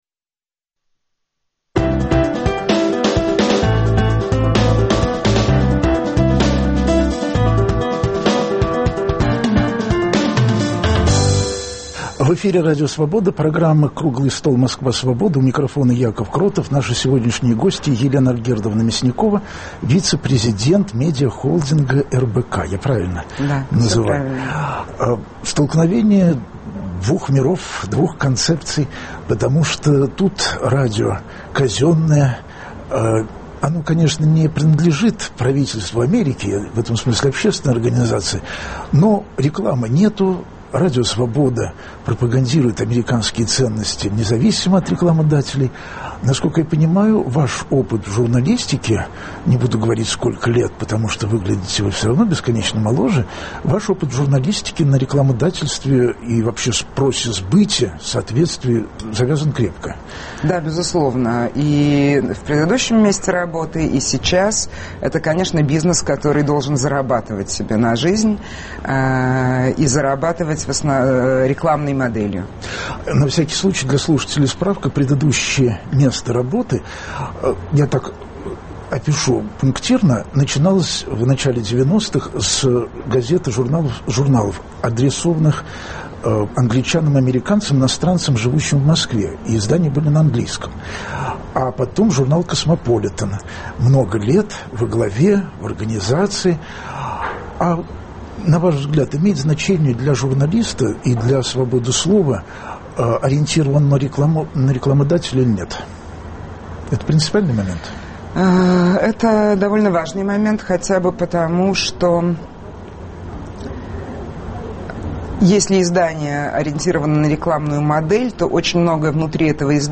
Круглый стол: Москва Свободы